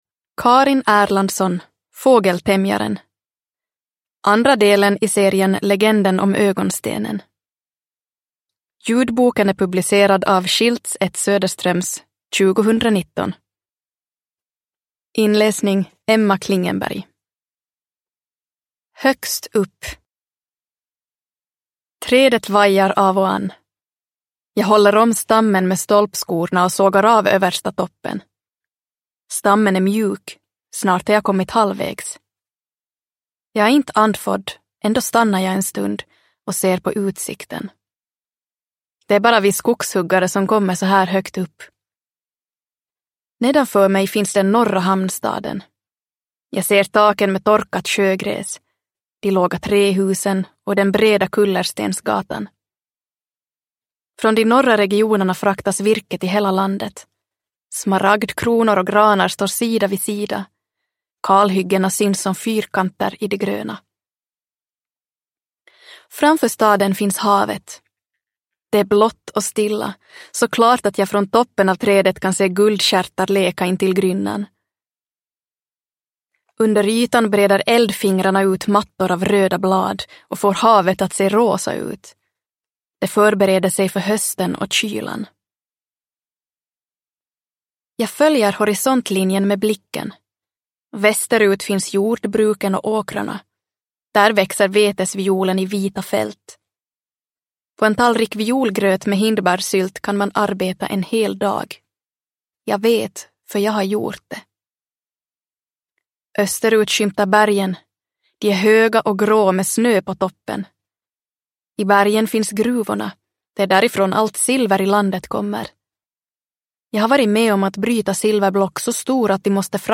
Fågeltämjaren – Ljudbok